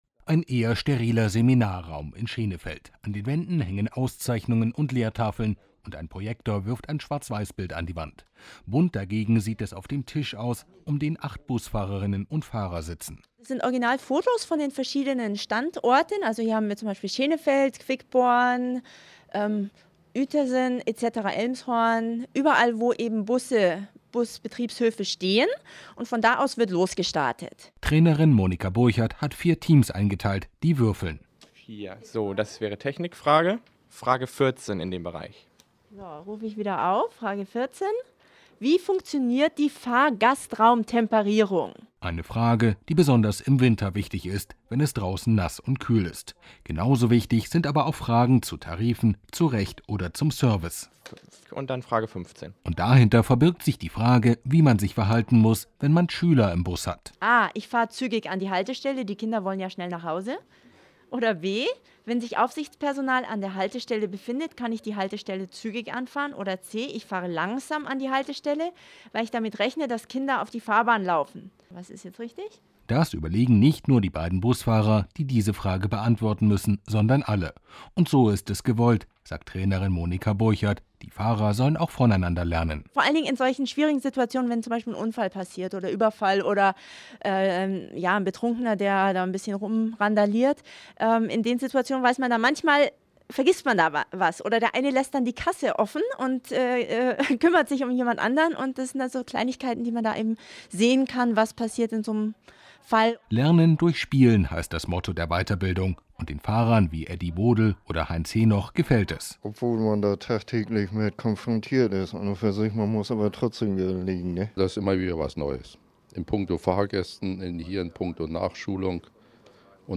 Radiobericht vom 09.02.09